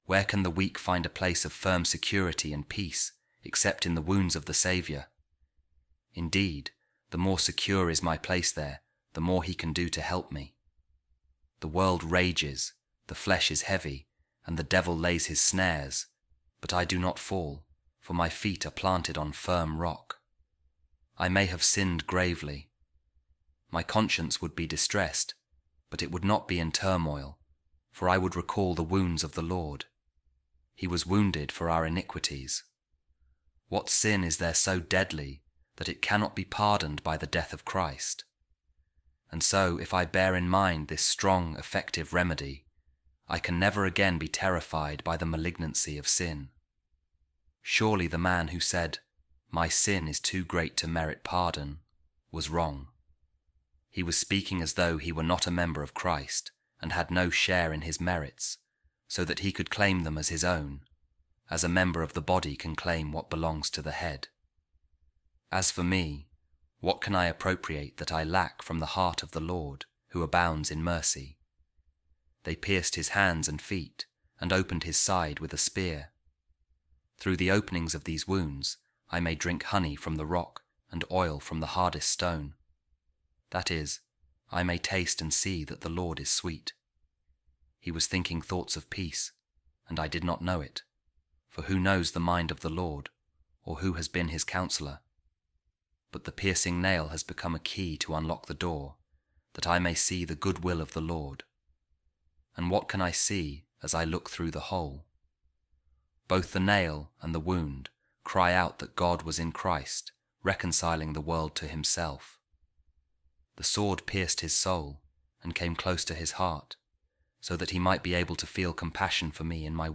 A Reading From The Sermons Of Saint Bernard On The Song Of Songs | Where Sin Abounded, Grace Superabounded